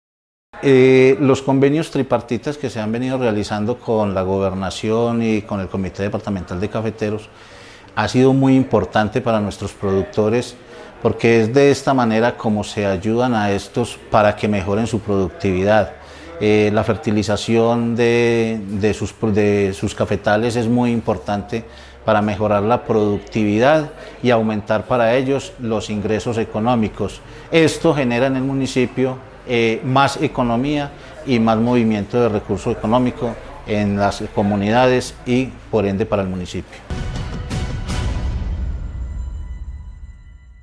Alirio Restrepo Serna, secretario de Agricultura y Medio Ambiente de Filadelfia.